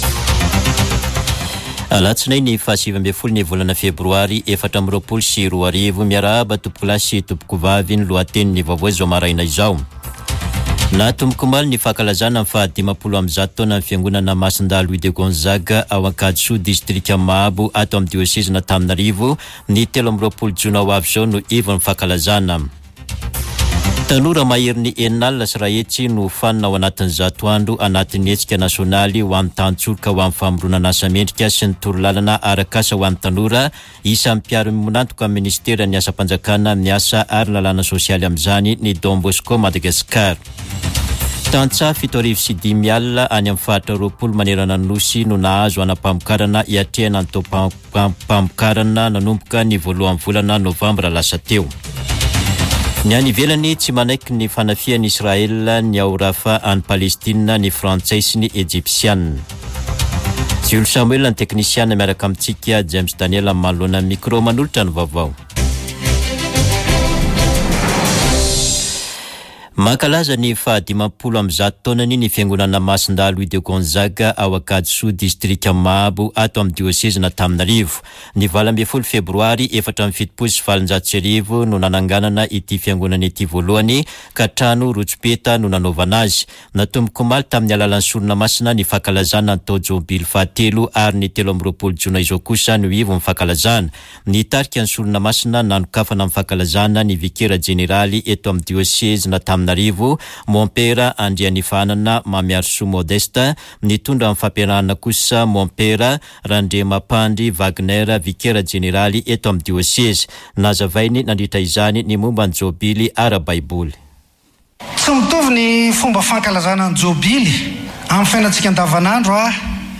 [Vaovao maraina] Alatsinainy 19 febroary 2024